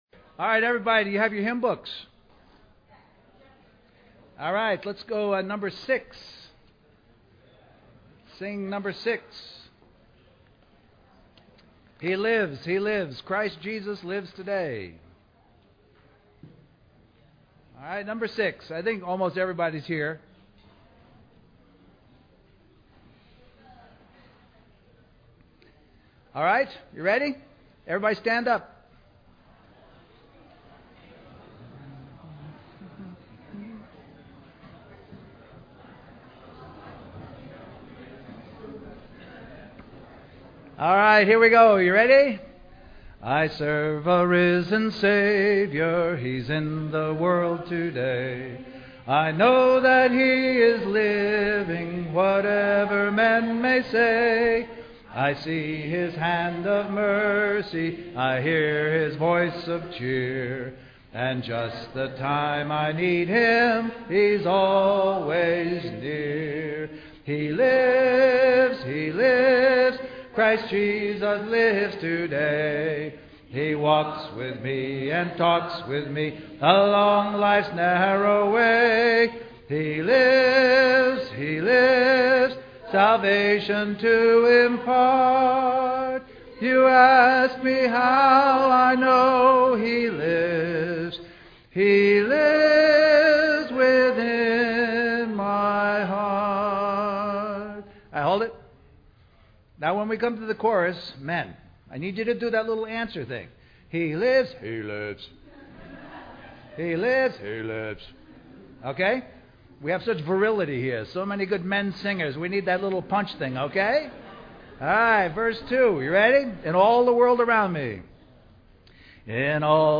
A collection of Christ focused messages published by the Christian Testimony Ministry in Richmond, VA.
West Coast Christian Conference